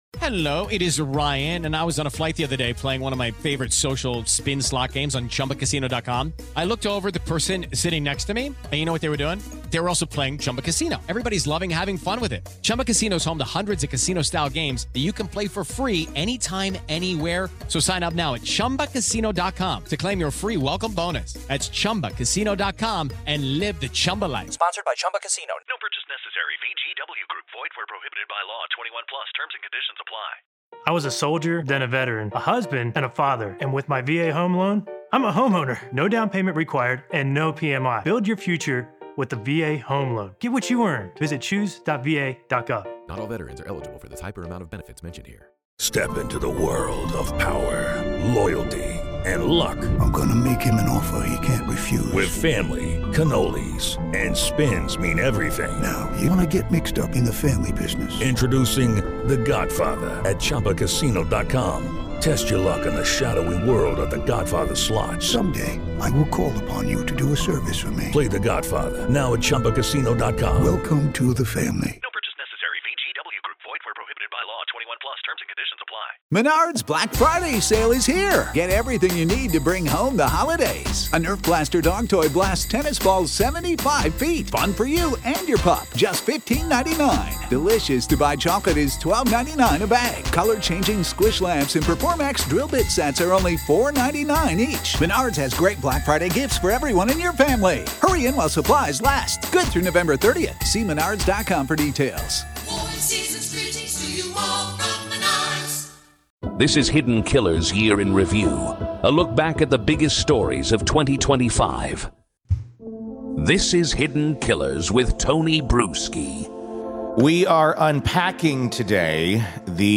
The conversation dives deep into the psychology of incremental abnormality — how small behavioral shifts go unnoticed until the monster is fully formed.